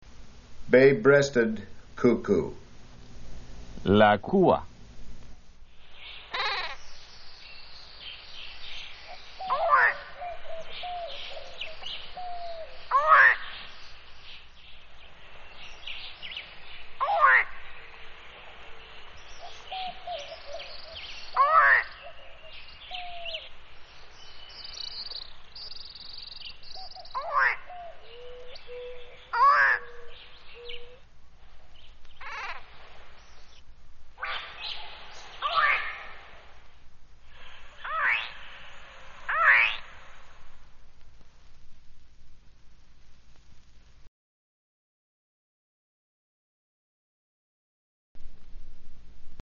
Bird Sounds from Hispaniola
Bay-breasted-Cuckoo
Bay-breasted-Cuckoo.mp3